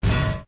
sounds / footsteps / iron2.ogg
iron2.ogg